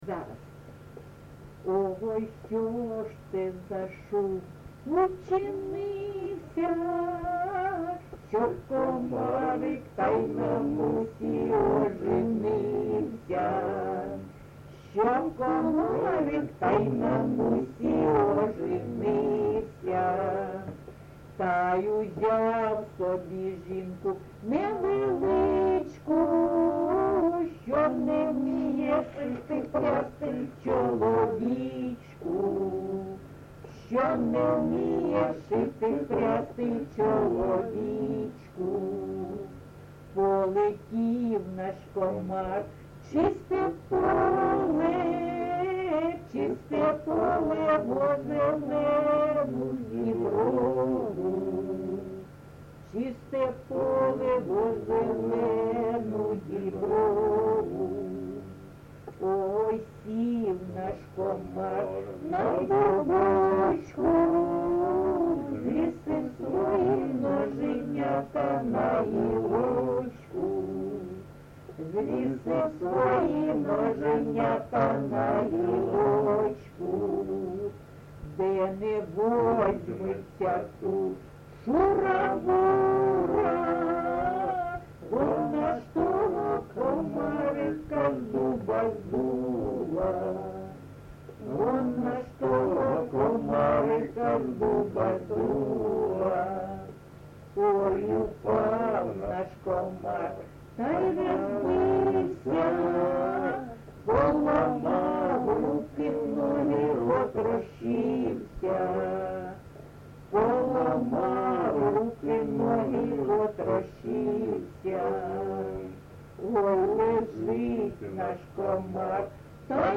ЖанрЖартівливі
Місце записус. Чорнухине, Алчевський район, Луганська обл., Україна, Слобожанщина